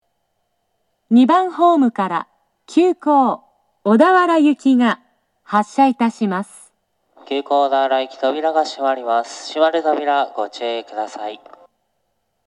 2010年11月より接近放送の冒頭に、『いきものがかり』の楽曲、「YELL」が接近メロディーとして流れています。
自動放送
（女性）
接近放送
急行　小田原行（6両編成）の接近放送です。